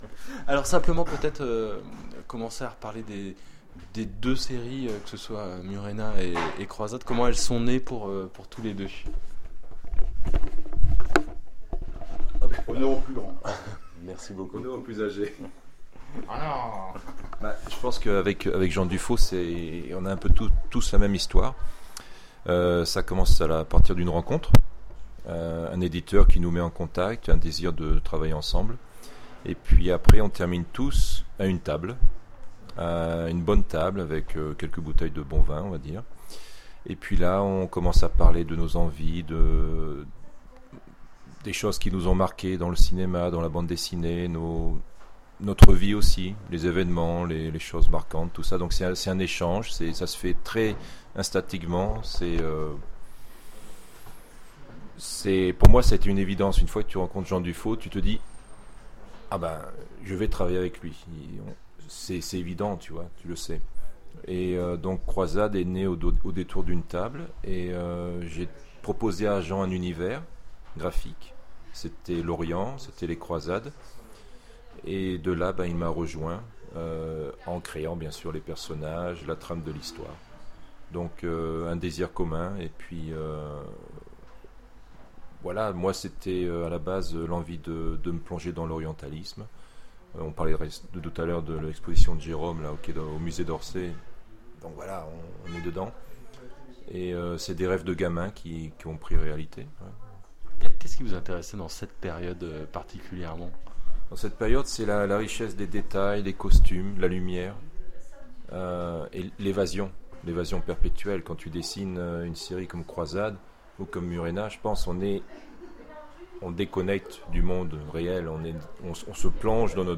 ITW
Dialogue avec deux grands dessinateurs...